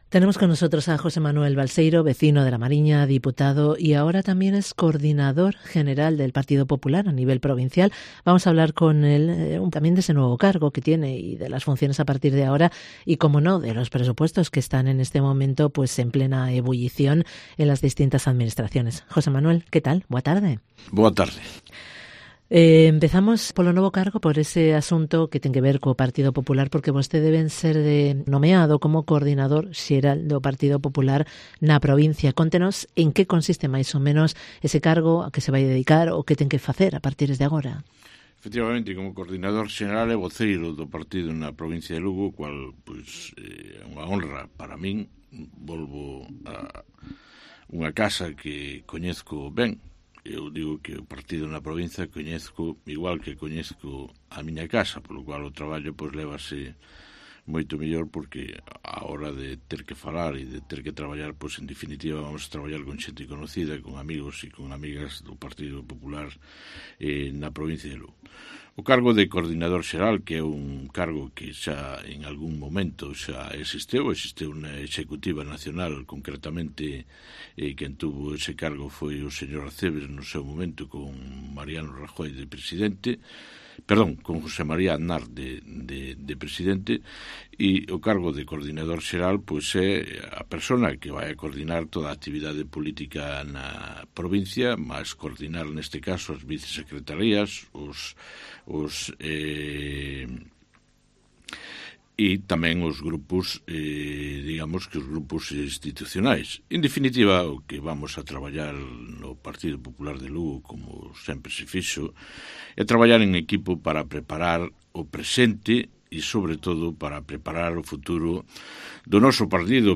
Entrevista con JOSÉ MANUEL BALSEIRO, coordinador general y portavoz del PP en la provincia de Lugo